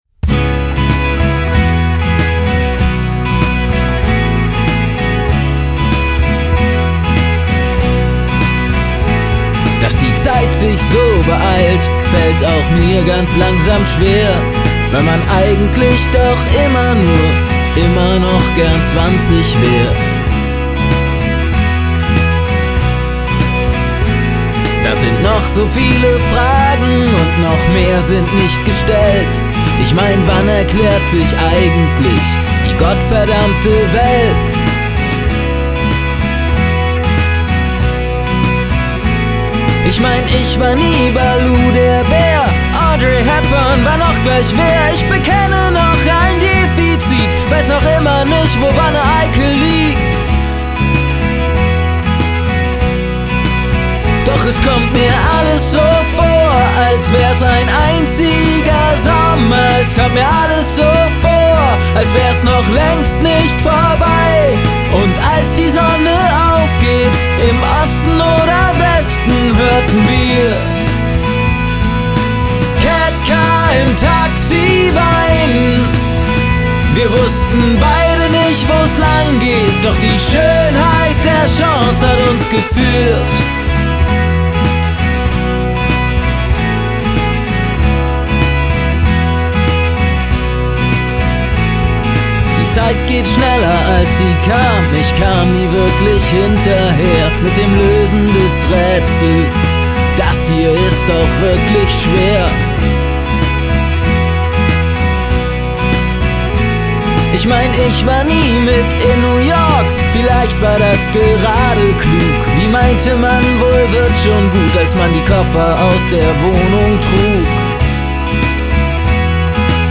Genre: Alt. Rock.